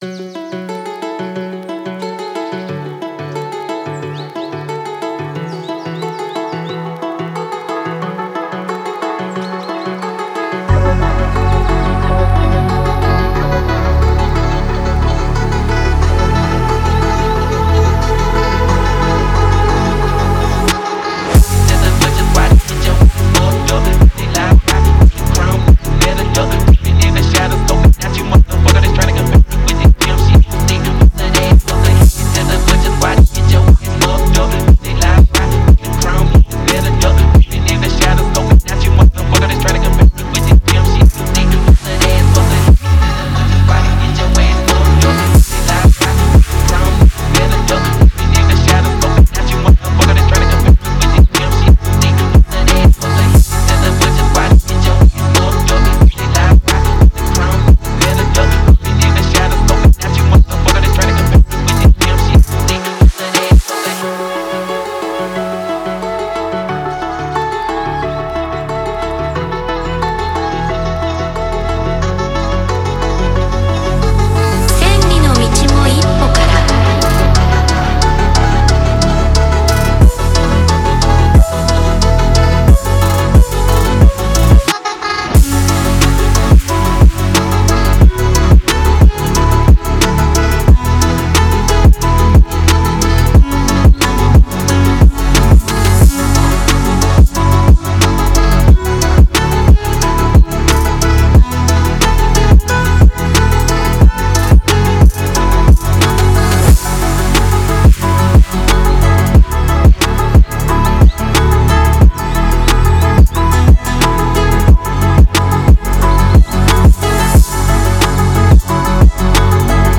Фонк треки
Phonk